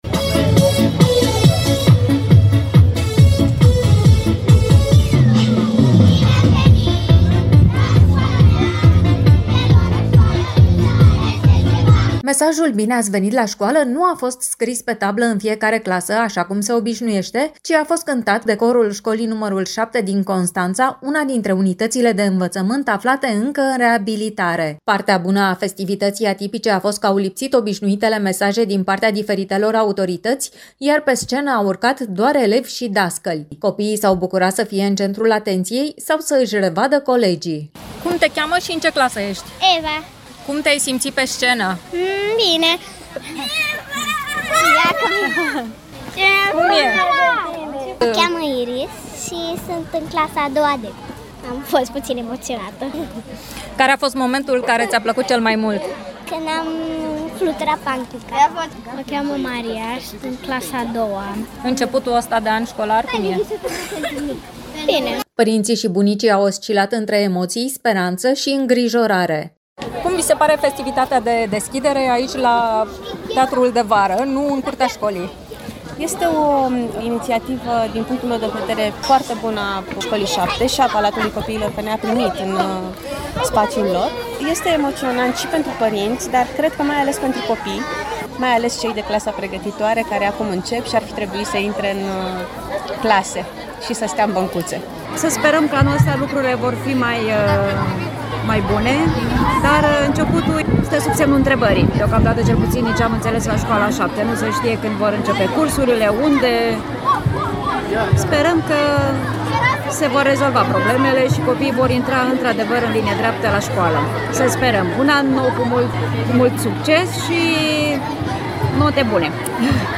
Totuși, pentru ca prima zi de școală să rămână o amintire frumoasă, elevii au avut parte de o festivitate de deschidere la Teatrul de Vară al Palatului Copiilor.